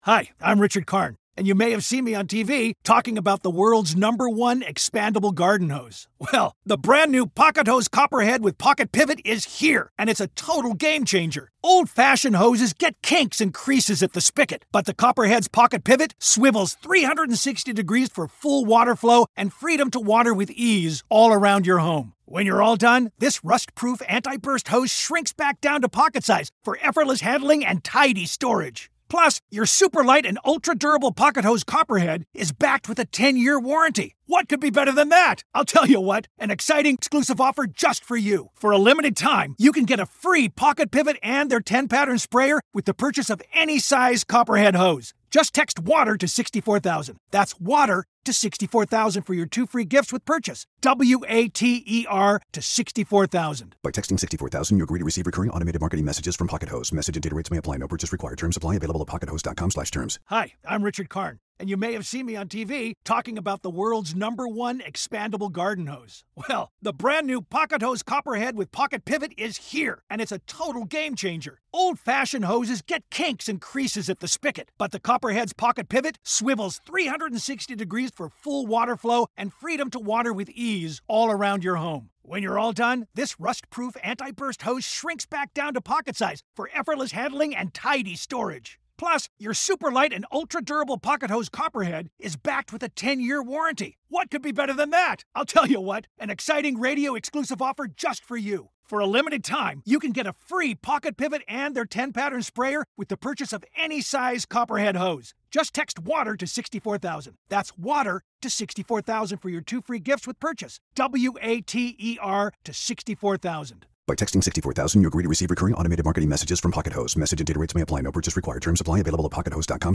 My HoneyDew this week is magician and comedian John van der Put a.k.a. Piff the Magic Dragon!